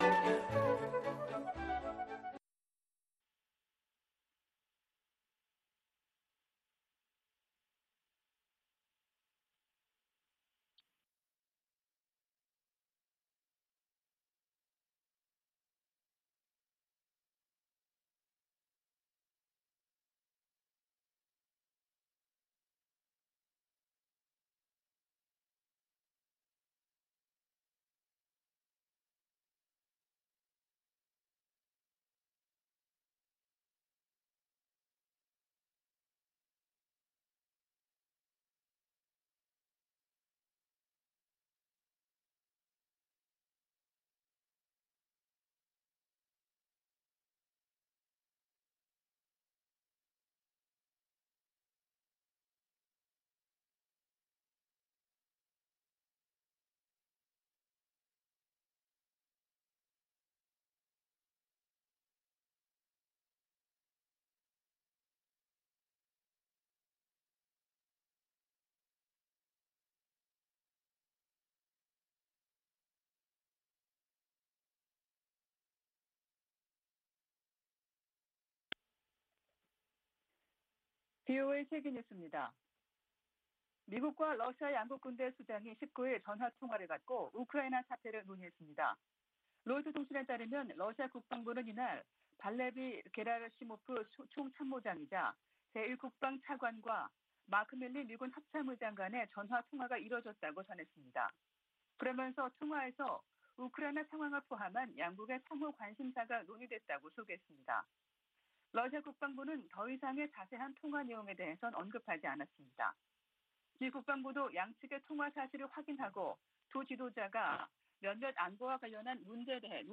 VOA 한국어 아침 뉴스 프로그램 '워싱턴 뉴스 광장' 2022년 5월 20일 방송입니다.